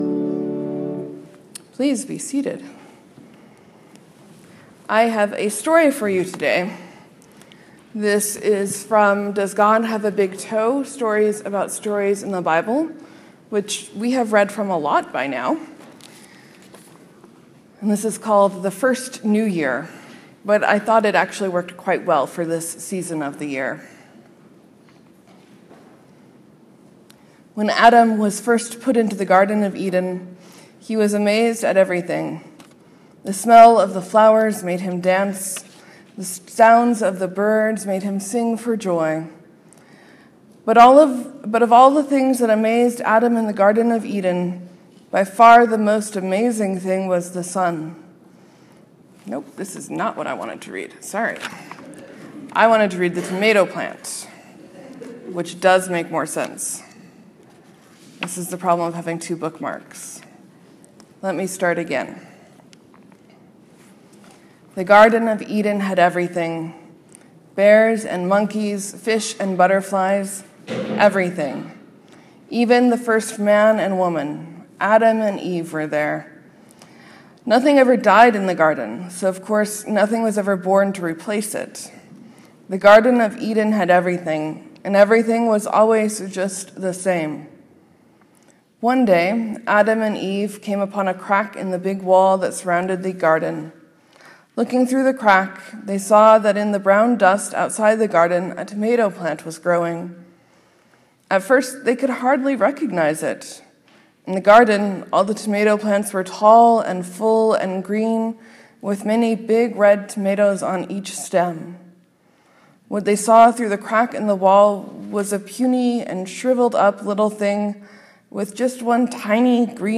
Morsels & Stories: I read a story from Does God have a Big Toe?
Sermon: Today we give thanks for all that we have, for all of the ways we’re different, and for our ability to share.